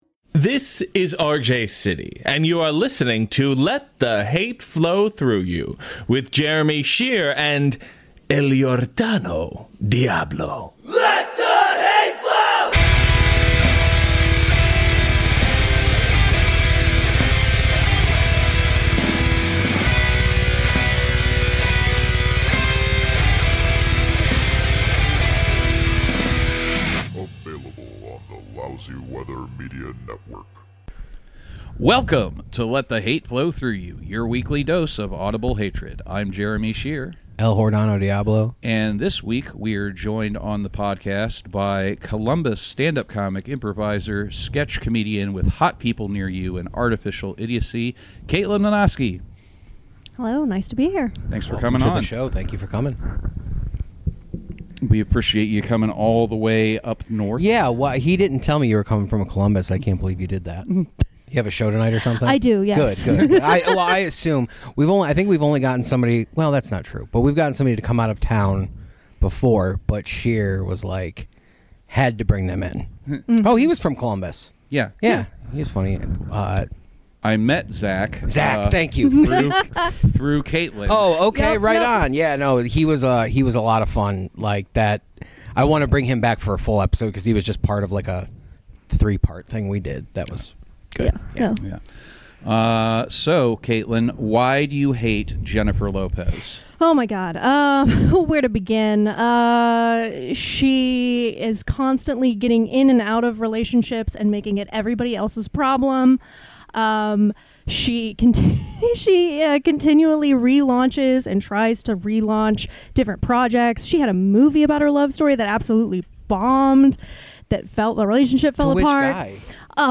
Two large breasted men interview a makeup nerd and triple threat Columbus comedian about her hatred of Jennifer Lopez